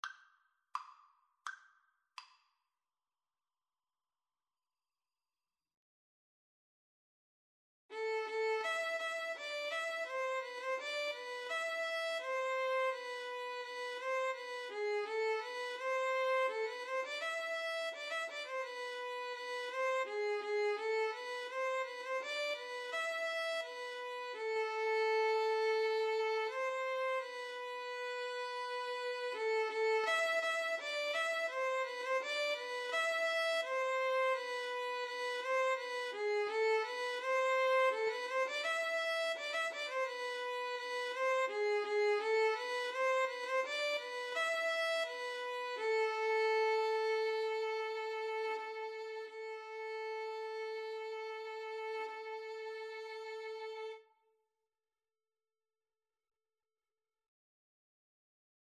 Free Sheet music for Violin-Cello Duet
Steady two in a bar ( = c. 84)
A minor (Sounding Pitch) (View more A minor Music for Violin-Cello Duet )
2/2 (View more 2/2 Music)